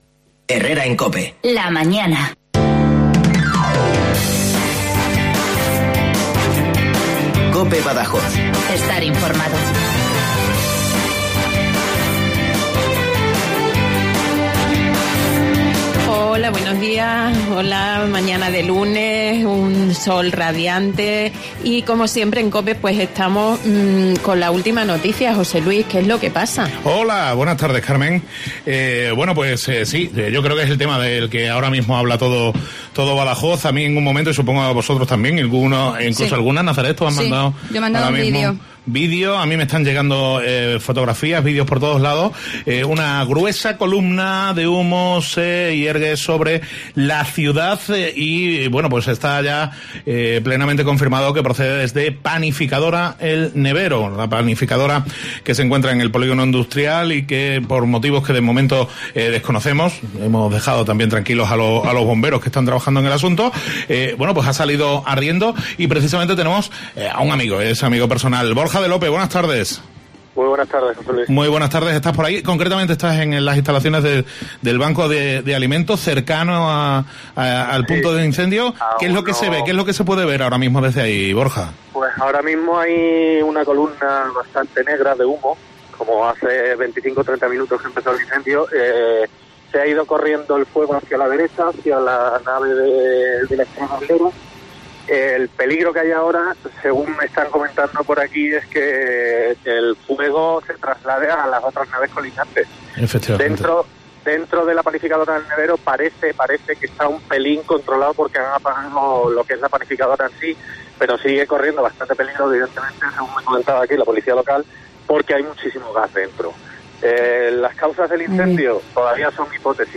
Hoy en Herrera en COPE Badajoz hemos hablado de moda, de las tendencias que nos llegan del MOMA, en Madrid. Pero como la actualidad manda, nos han contado en directo lo que pasaba en la Panificadora de El Nevero, pasto de las llamas esta mañana. Un oyente llamaba al programa para contárnoslo.